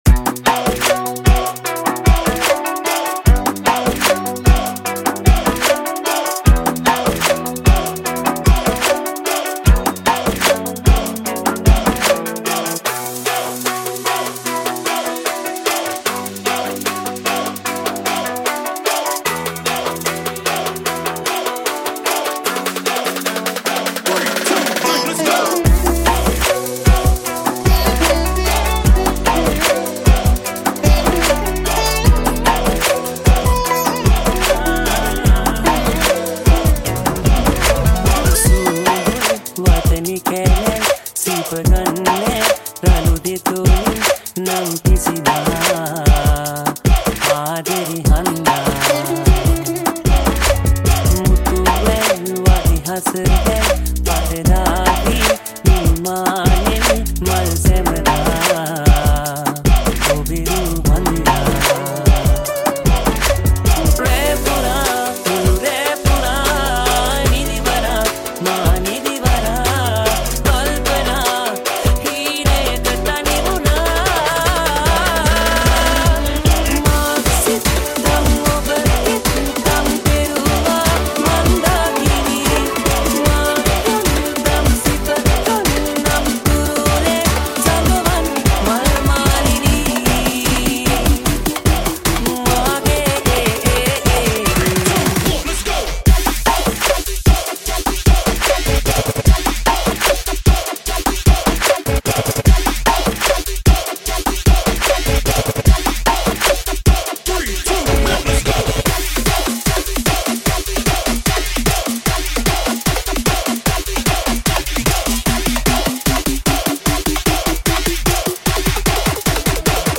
High quality Sri Lankan remix MP3 (2.7).